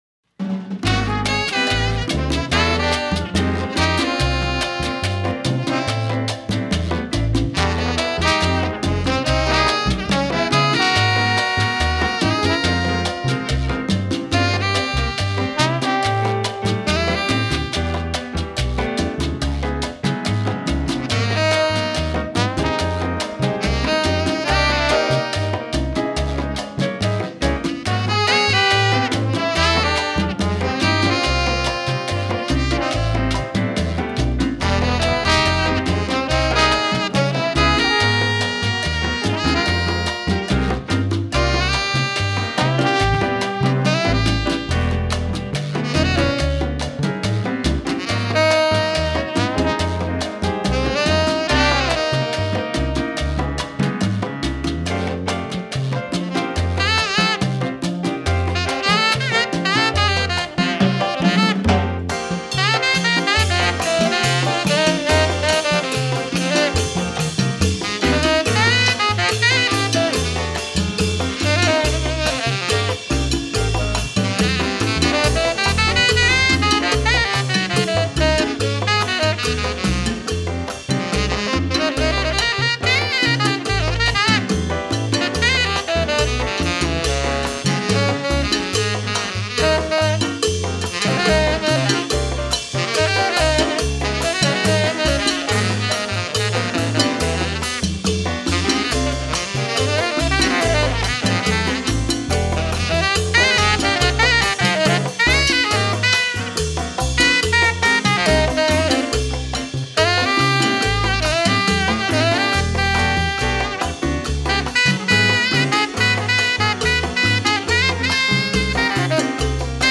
FILE: Jazz